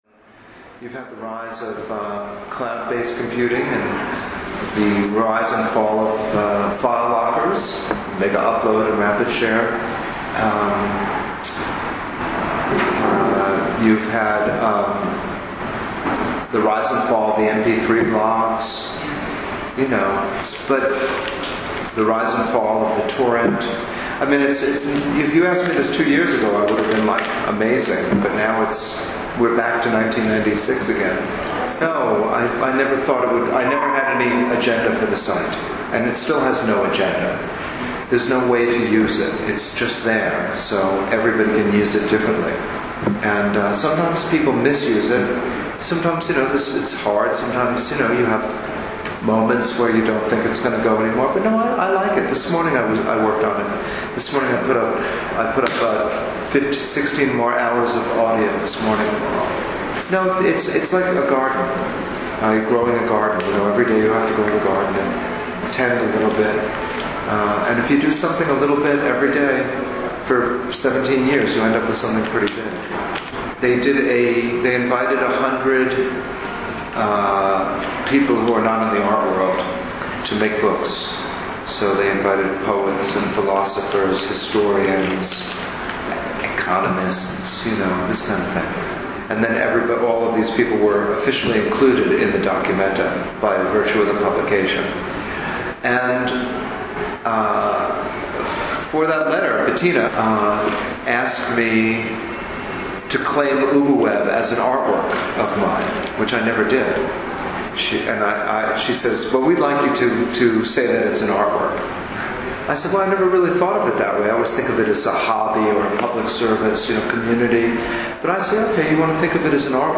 ▣ Audio Archive: Kenneth Goldsmith Interview (Selected Excerpts) [2013]